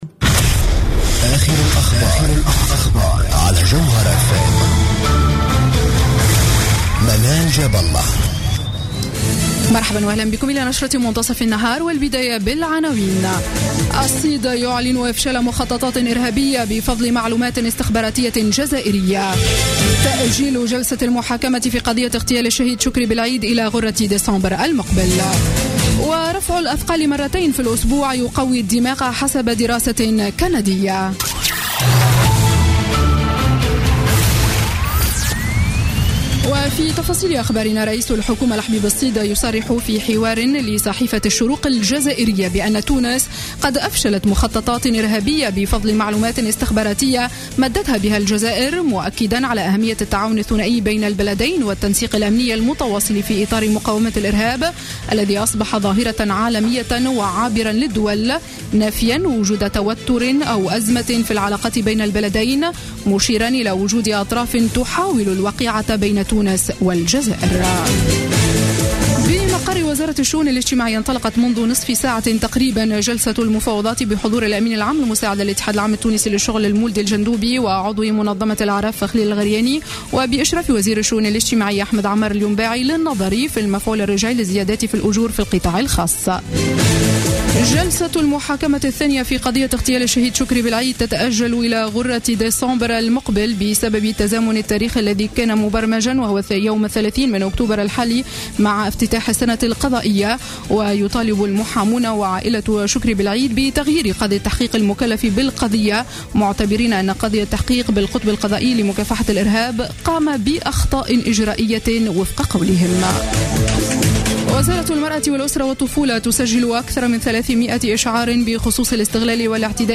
نشرة أخبار منتصف النهار ليوم الثلاثاء 27 أكتوبر 2015